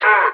DDW2 CHANT 1.wav